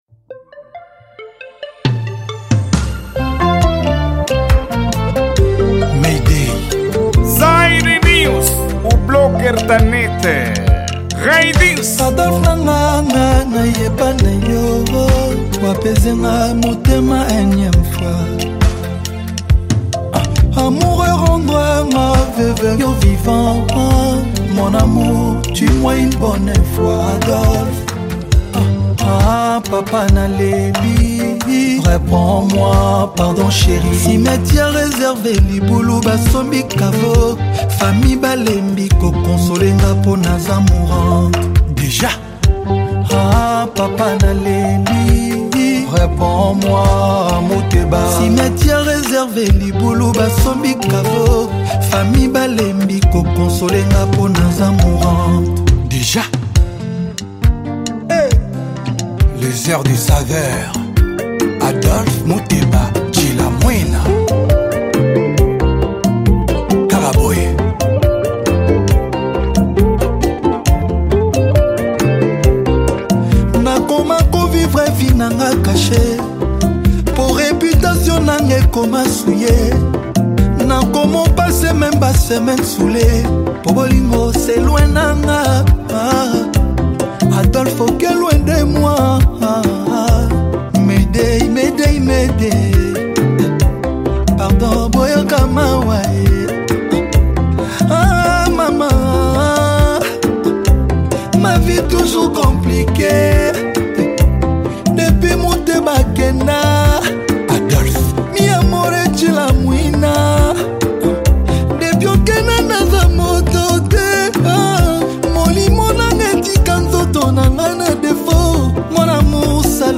Gênero:Rumba